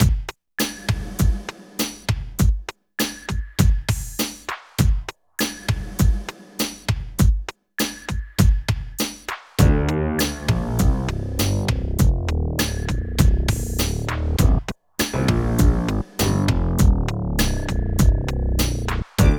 12 LOOP A -L.wav